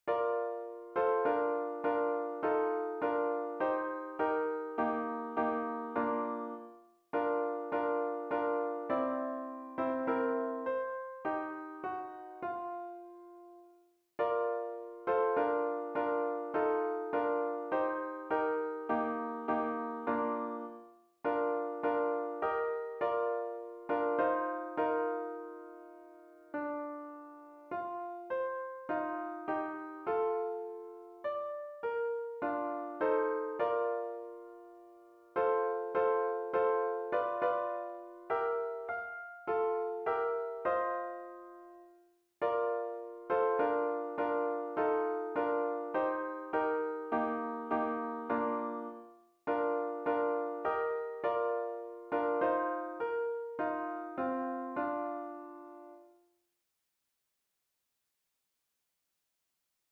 Female Trio.